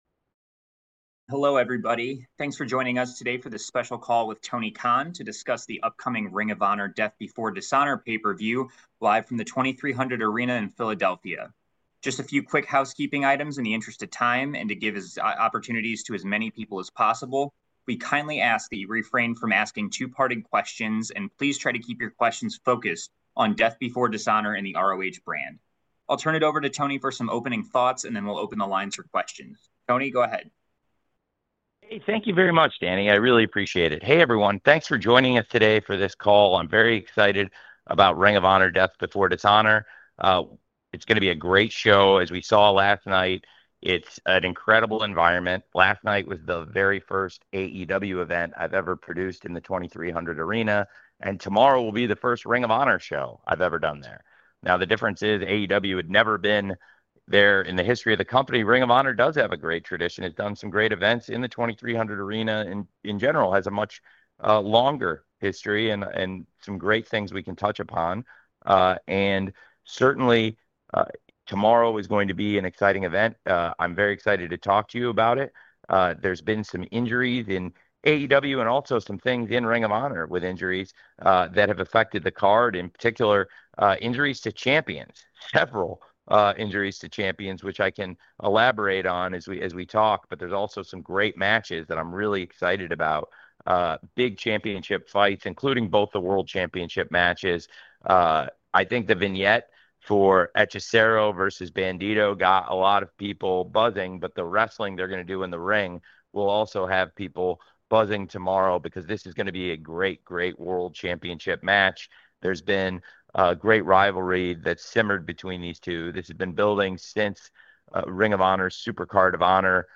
Tony Khan speaks to media ahead of ROH Death Before Dishonor 2025 about ROH's TV deal, Deonna Purazzo, Chris Jericho and more!